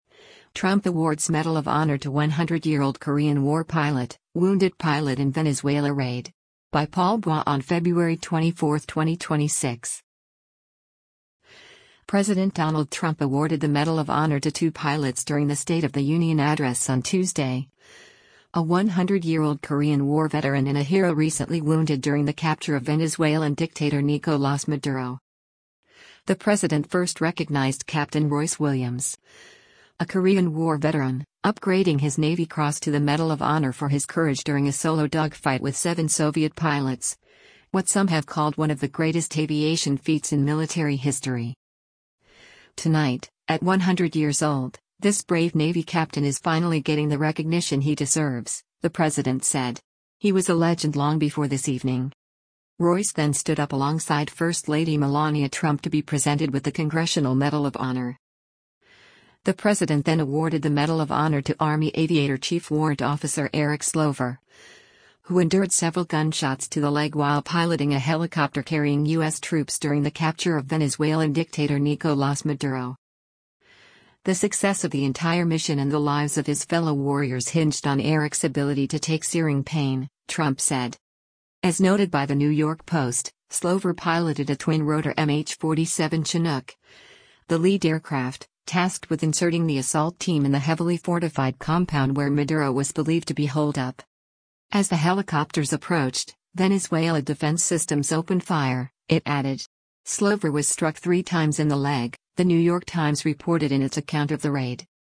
President Donald Trump awarded the Medal of Honor to two pilots during the State of the Un